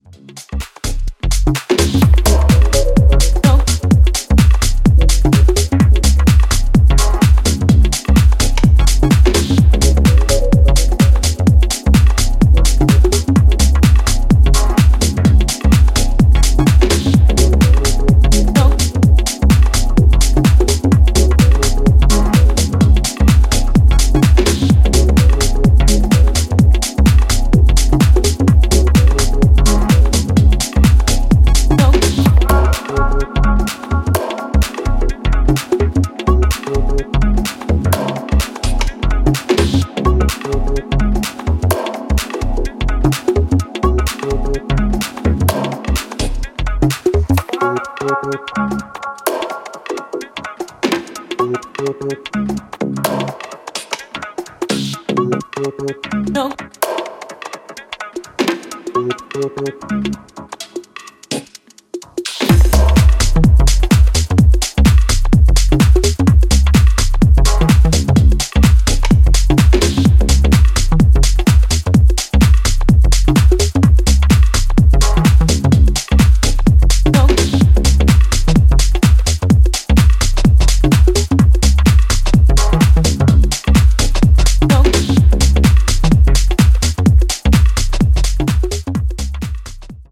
ジャンル(スタイル) TECH HOUSE / DEEP HOUSE